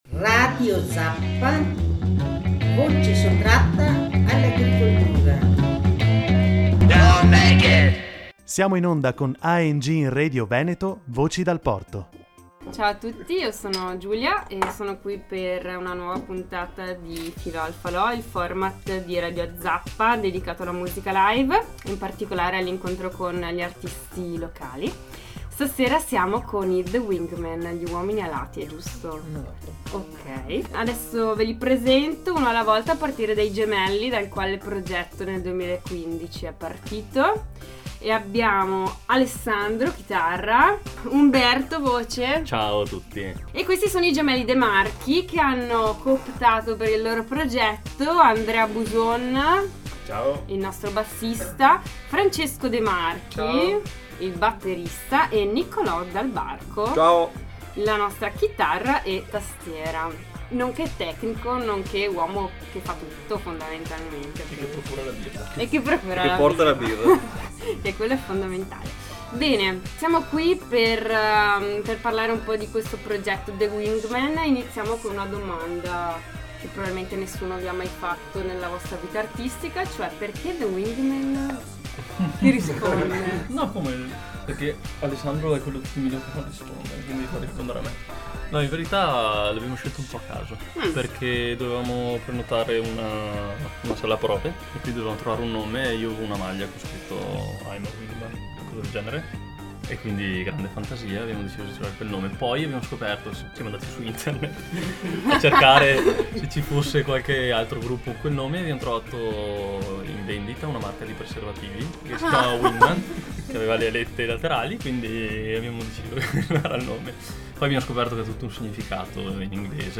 Filò al falò #7 – The Wingman Vicenza, 27 novembre 2019 Ospiti a Falò al Falò The Wingman, band indie vicentina.
Sono pronti a tornare in studio e registrare gli ultimi brani, intanto nel podcast un po’ di chiacchiere, risate e due pezzi tutti da ascoltare: cuffie nelle orecchie e via.